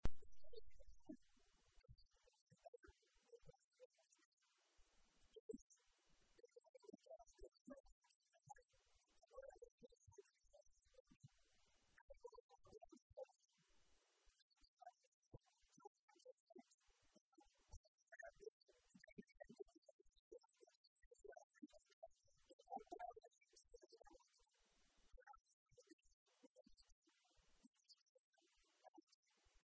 Fernando Mora, portavoz de Sanidad del Grupo Socialista
Cortes de audio de la rueda de prensa